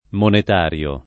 monetario [ monet # r L o ]